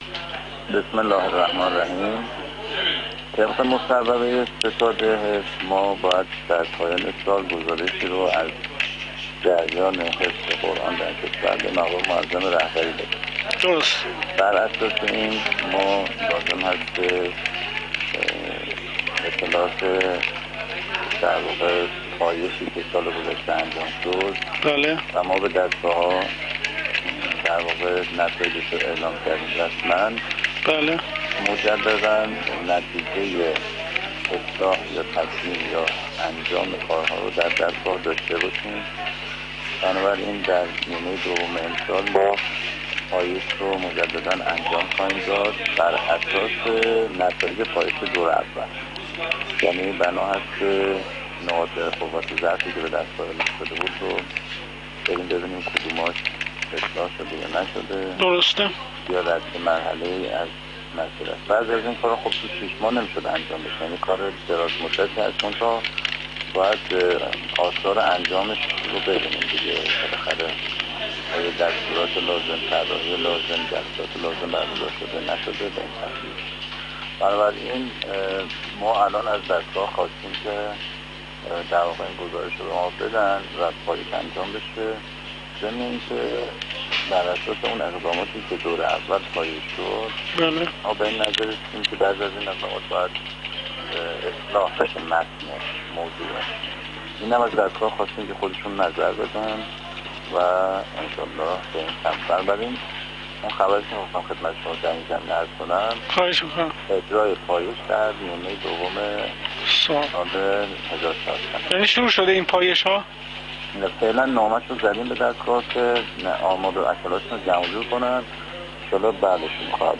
در گفت‌وگو با خبرنگار ایکنا